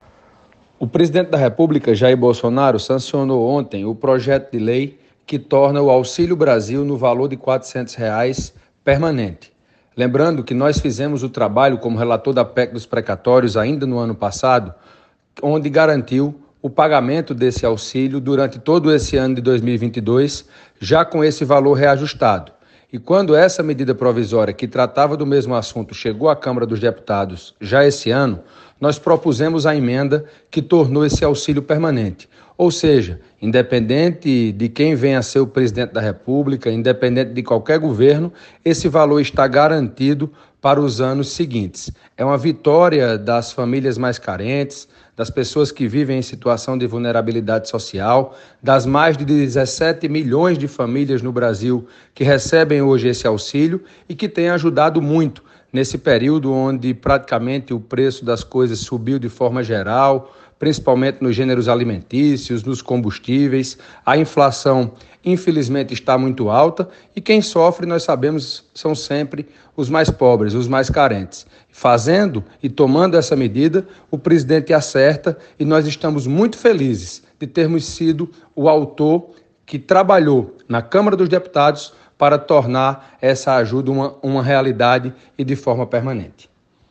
O deputado federal Hugo Motta (Republicanos) comentou, nesta quinta-feira (19), a respeito da sanção da Medida Provisória (MP) que tornou o Auxílio Brasil, no valor de R$ 400, um benefício permanente. Para o parlamentar, a instituição do auxílio representa uma vitória para o povo brasileiro carente, que são os mais atingidos pela alta da inflação.